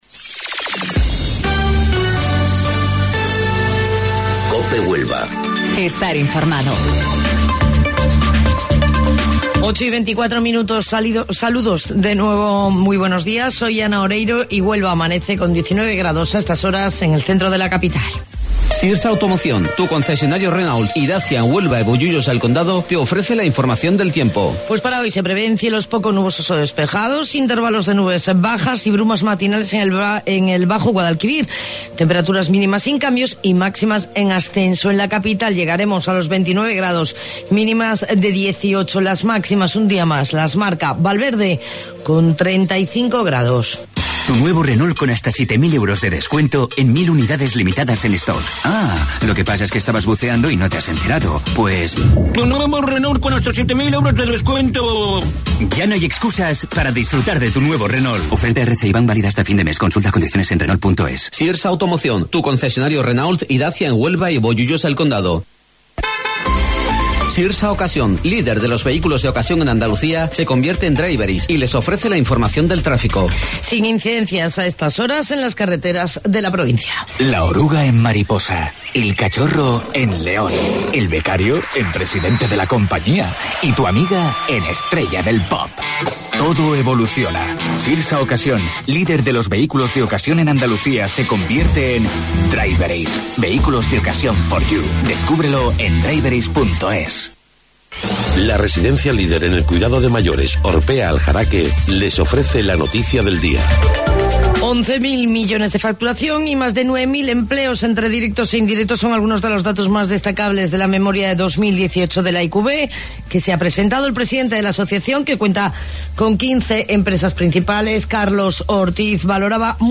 AUDIO: Informativo Local 08:25 del 18 de Julio